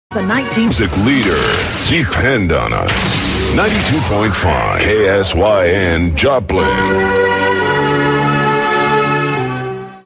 KSYN Top of the Hour Audio: